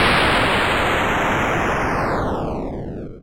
描述：复古视频游戏8位爆炸
Tag: 复古 爆炸 8位 视频游戏